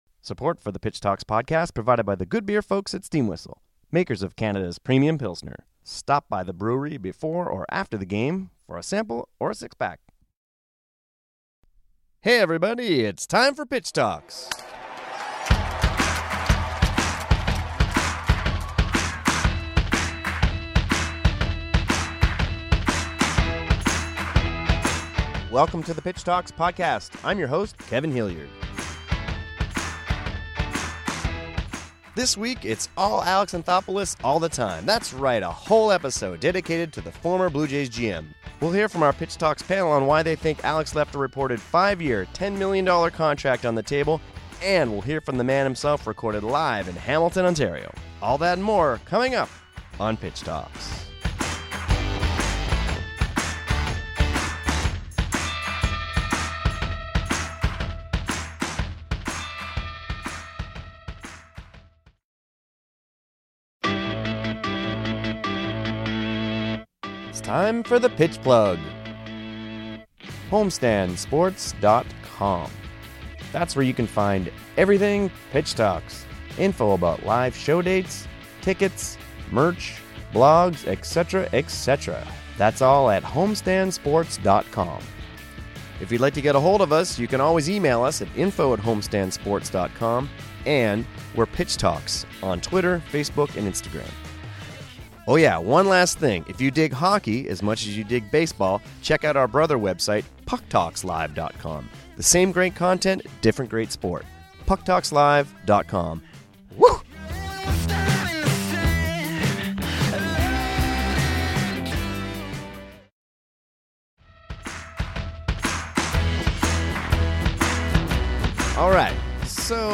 Then...we hear from the man himself: Alex Anthopoulos recorded live at Pitch Talks in Hamilton, what later learned was the very day he met with incoming team President Mark Shapiro.